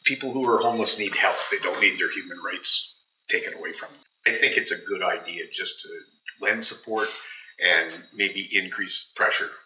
Councillor Stephen Robinson says the clause steps on human rights.